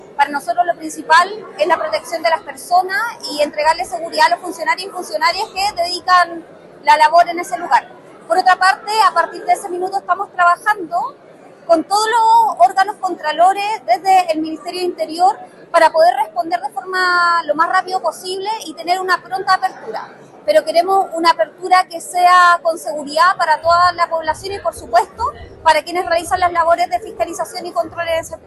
La autoridad señaló que están trabajando para reabrir la ruta lo antes posible, pero que la prioridad es garantizar la seguridad.